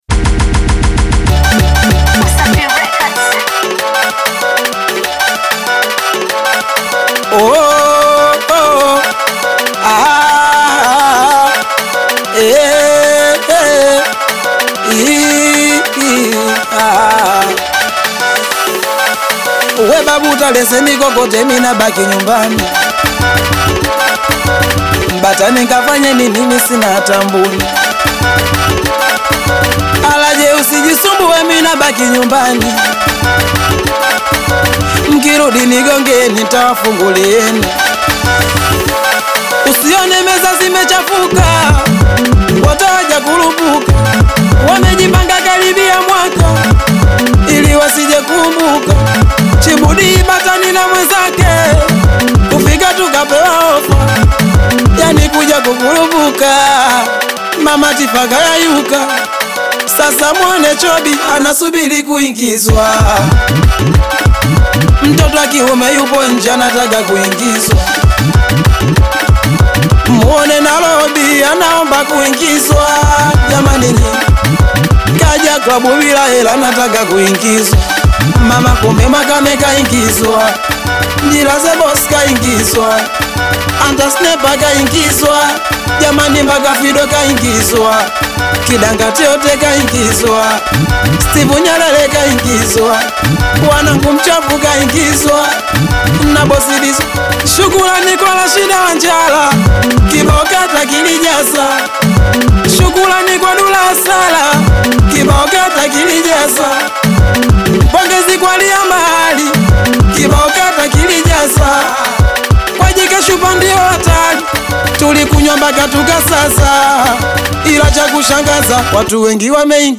that combines rapid-fire rhythms with sharp
Genre: Singeli